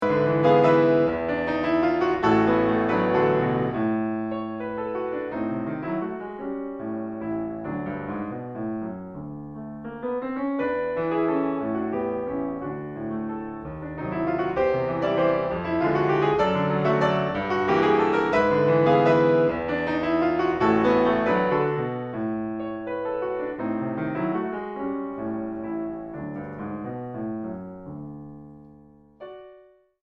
En re mayor. Con moto 1.46